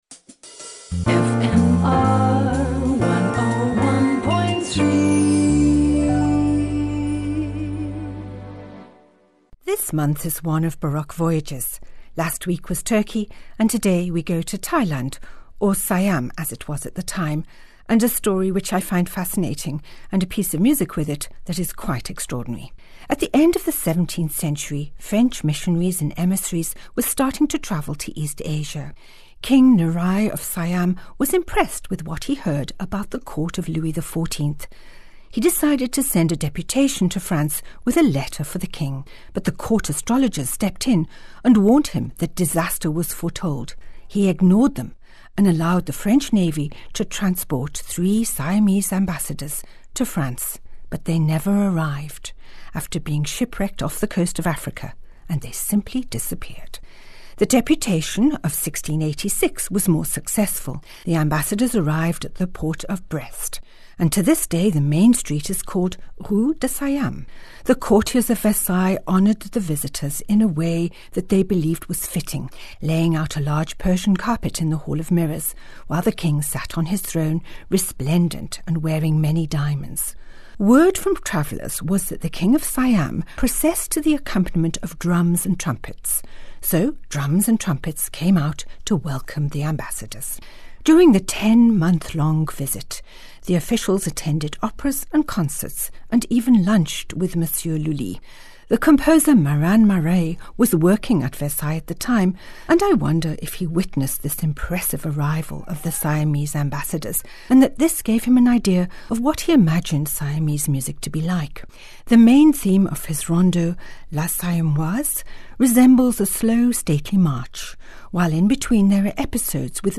From the esteemed Handel having his life saved by his jacket button while duelling to the latest discoveries of Baroque scores in dusty attics. Each weekly Bon Bon is accompanied by a piece of Baroque music which ties in with the story.